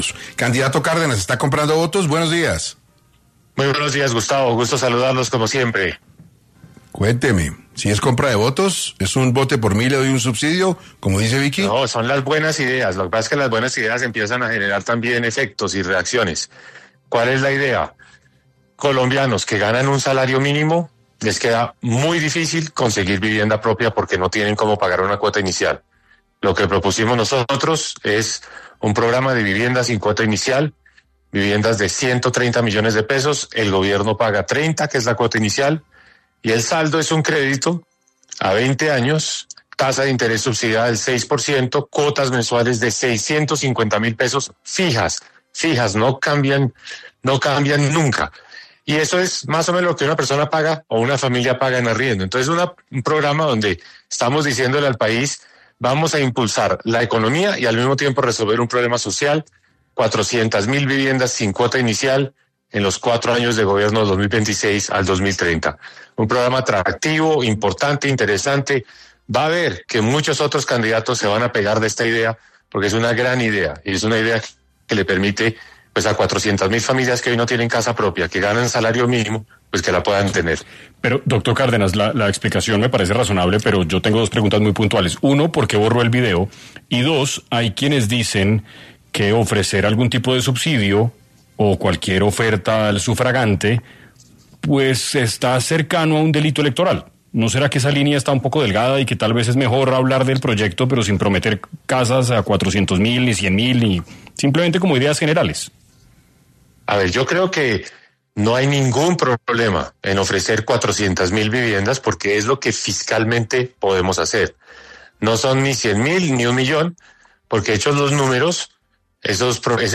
El precandidato presidencial, Mauricio Cárdenas, habló en 6AM del programa que está proponiendo en vivienda para los colombianos
En entrevista con 6AM el precandidato Mauricio Cárdenas afirmó que no es así: “Son las buenas ideas, lo que pasa es que las buenas ideas empiezan a generar también efectos y reacciones”, aseguró.